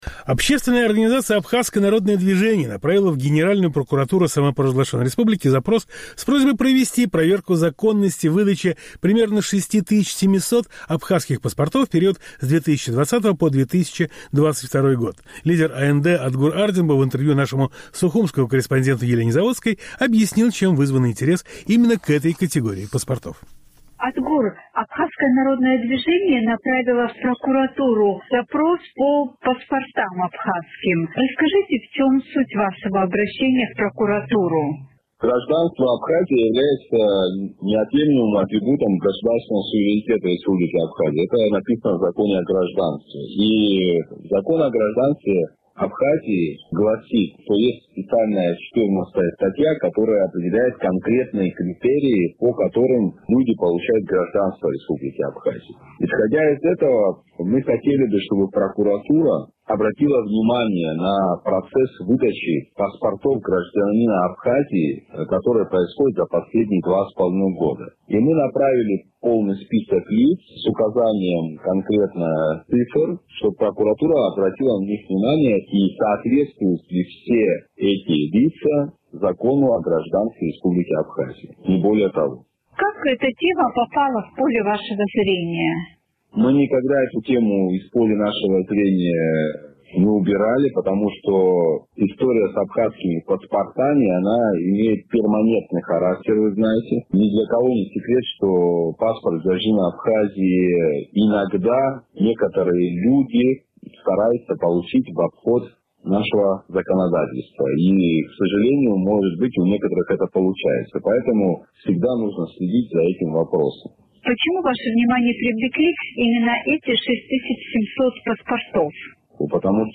Политика